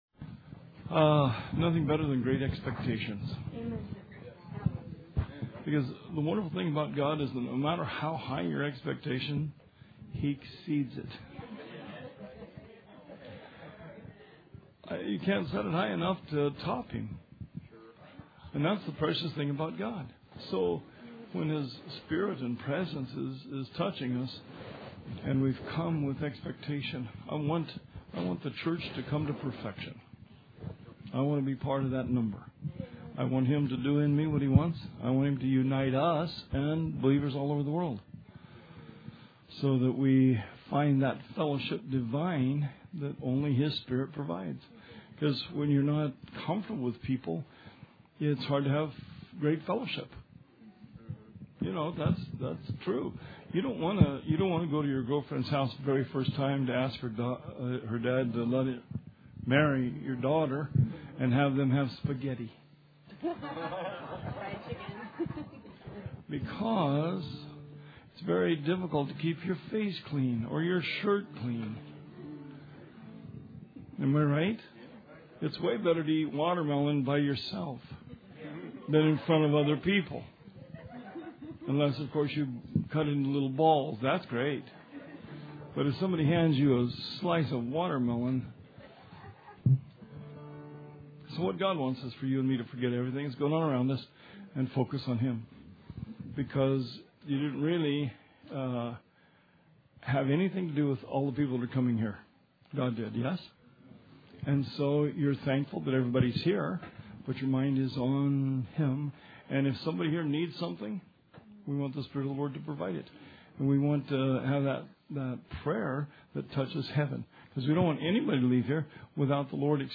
Sermon 11/25/16